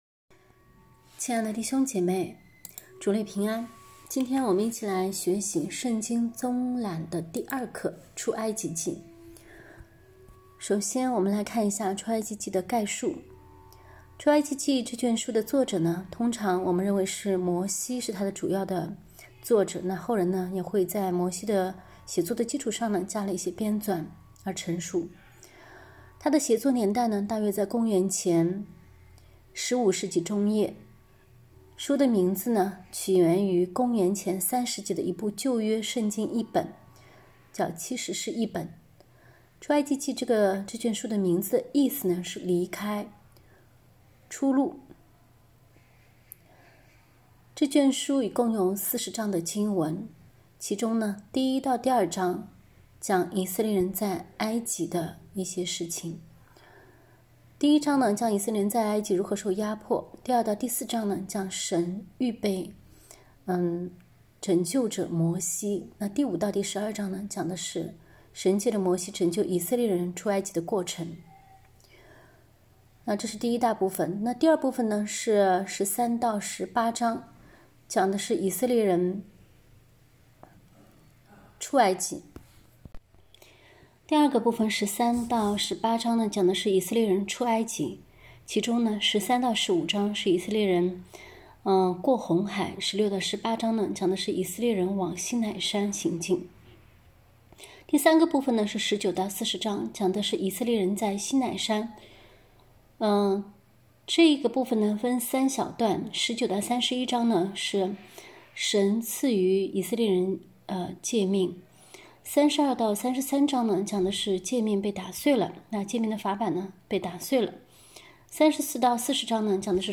课程音频： /wp-content/uploads/2022/11/圣经综览2-出埃及记.m4a 课程讲义： 圣经综览（二）——出埃及记 一、出埃及记概述 作者：摩西 年代: 约公元前15世纪 书名：本卷书的名字来源于公元前3世纪的一部旧约圣经译本《七十士译本》，意思是“离开”“出路”。 大纲：共40章 1-12章 以色列人在埃及 A 1章 以色列人受压迫 B 2-4章 神预备摩西 C 5-12章 拯救的过程 13-18章 以色列人出埃及 A 13-15章 过红海 B 16-18章 往西奈山 19-40章 以色列人在西奈山 A 19-31章 赐予诫命 B 32-33章 诫命打碎 C 34-40章 重赐诫命 内容： 出埃及记紧接着创世记，记载了雅各的后裔——以色列人如何在神的带领下离开埃及为奴之地，穿越沙漠，抵达西奈山山脚的旅程。